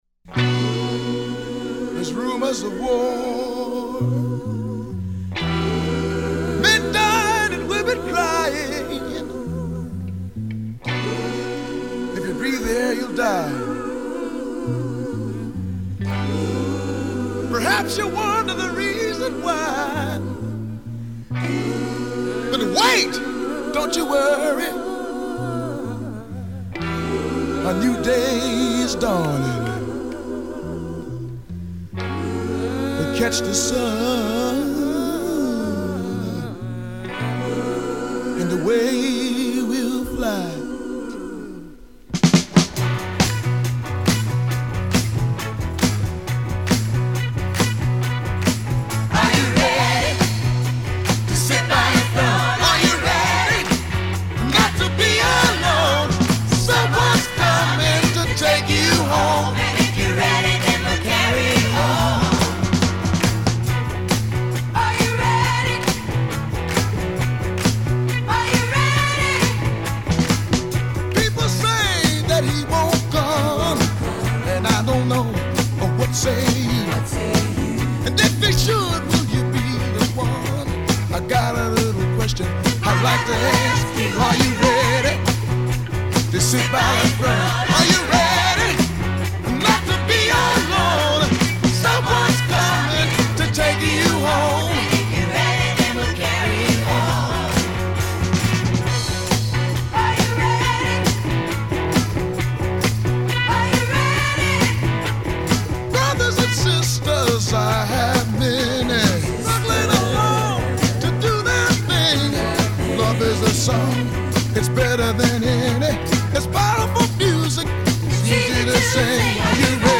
A fantastic fusion of funk, soul and rock